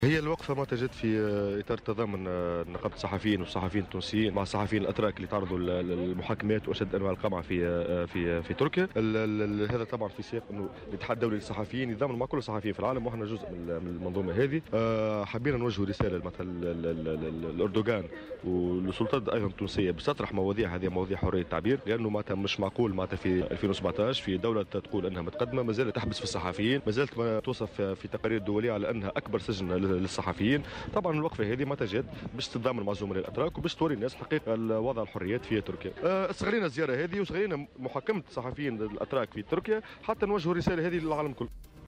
تصريح لمراسلة "الجوهرة أف أم"